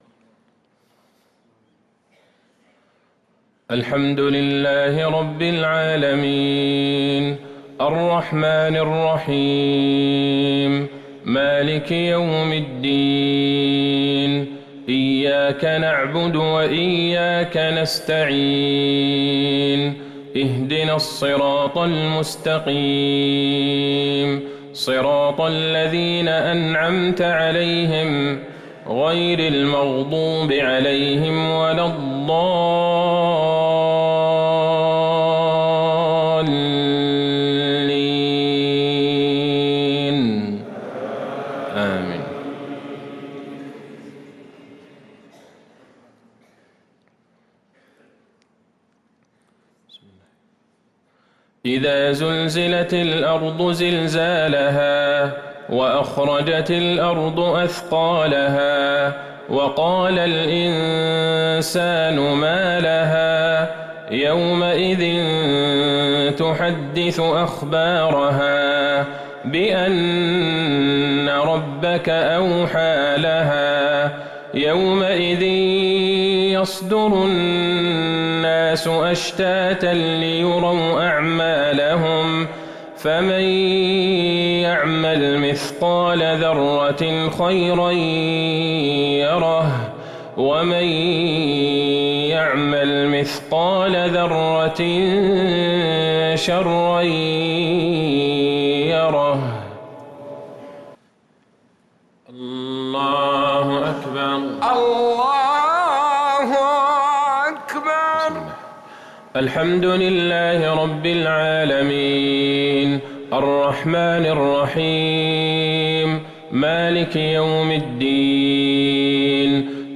مغرب الأحد 8-6-1444هـ سورتي الزلزلة و القارعة | Maghrib prayer from Surah AZ-zalzalah & Al-Qaria 1-1-2023 > 1444 🕌 > الفروض - تلاوات الحرمين